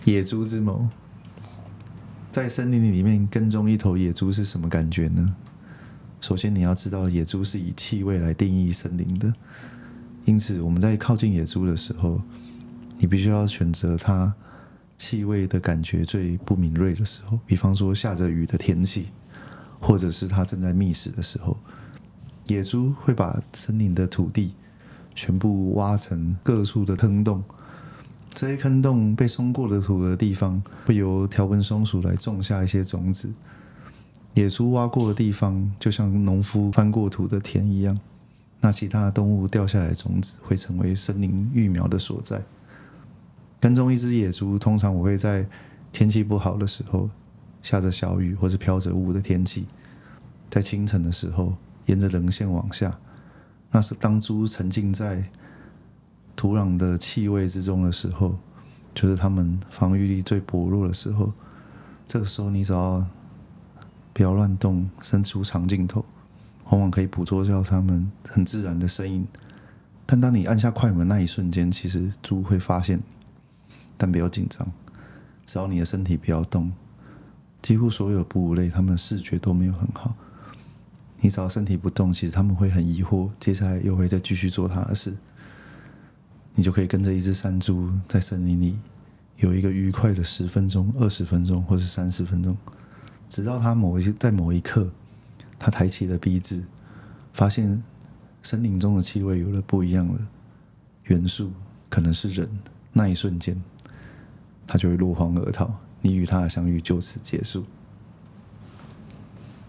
formosan-wild-boar.mp3